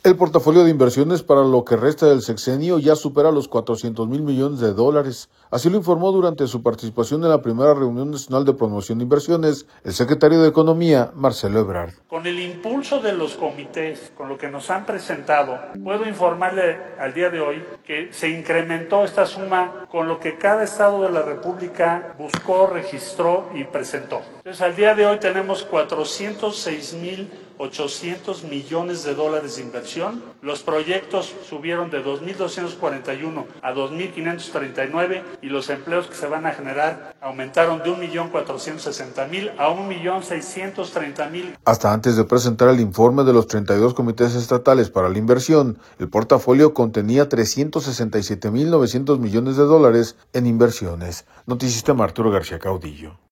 El portafolio de inversiones para lo que resta del sexenio ya supera los 400 mil millones de dólares, así lo informó durante su participación en la Primera Reunión Nacional de Promoción de Inversiones, el secretario de Economía, Marcelo Ebrard.